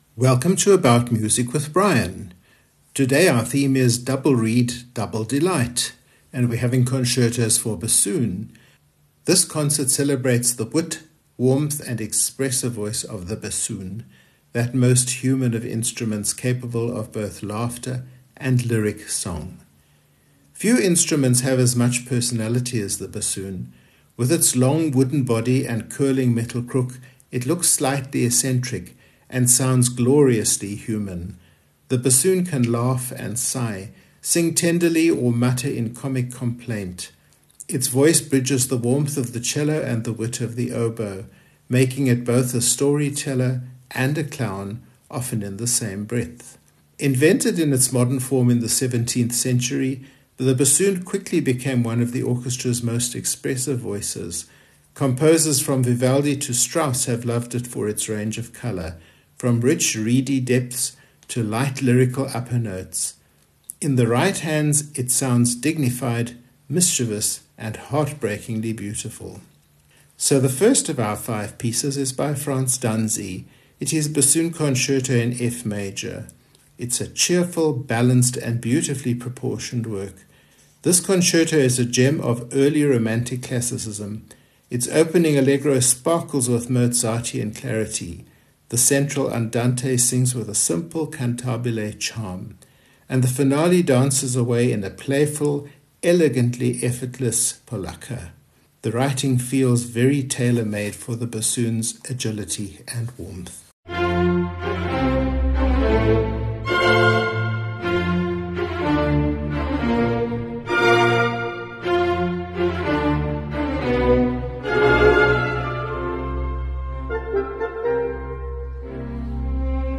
A concert celebrating the wit, warmth, and expressive voice of the bassoon — that most human of instruments, capable of both laughter and lyric song.
The bassoon can laugh and sigh, sing tenderly or mutter in comic complaint. Its voice bridges the warmth of the cello and the wit of the oboe, making it both a storyteller and a clown, often in the same breath.
Composer Work Notes Danzi, Franz (1763–1826) Bassoon Concerto in F major, Op. 4 Cheerful, Mozartean Classicism with graceful melodies and sparkling runs. The opening Allegro is bright and buoyant, the Andante lyrical and tender, and the Polacca finale dances with effortless elegance.
Winter, Peter von (1754–1825) Bassoon Concerto in C minor A work of late Classical poise tinged with Romantic feeling.
Elgar, Edward (1857–1934) Romance in D minor, Op. 62 A gentle English reverie – wistful, tender, and quietly noble.